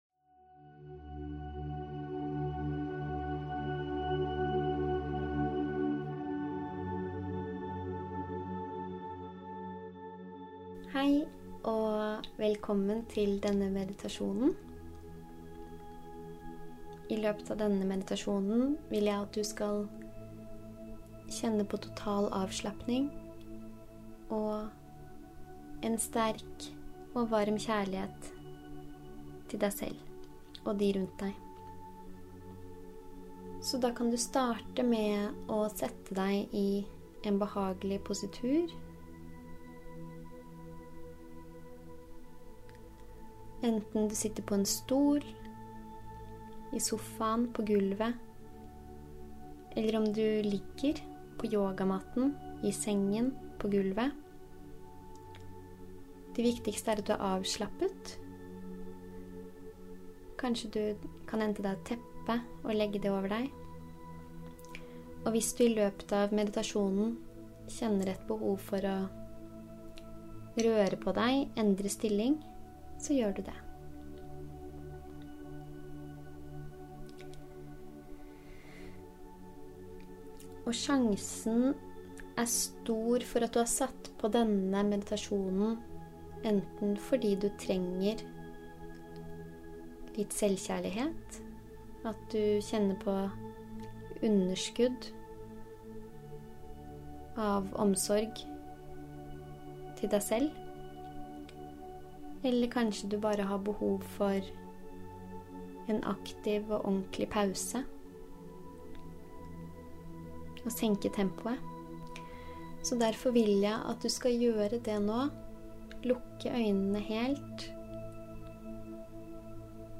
Guidet meditasjon: Selvkjærlighet